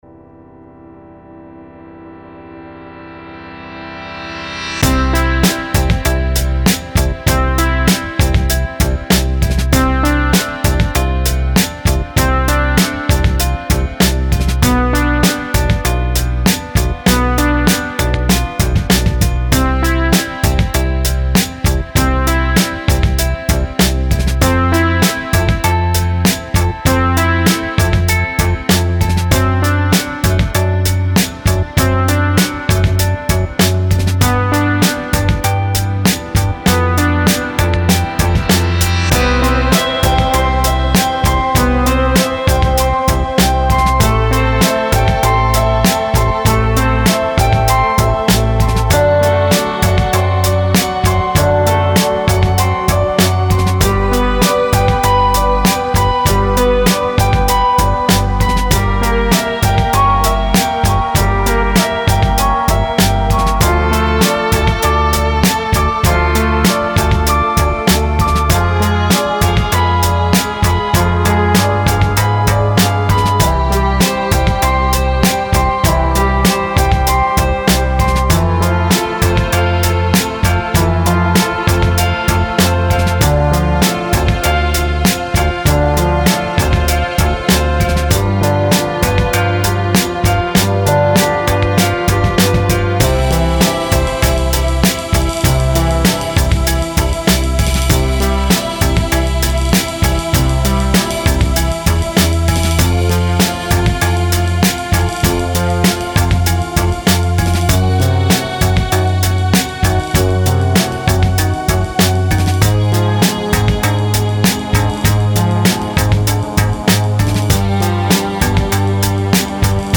Home > Music > Electronic > Bright > Medium > Floating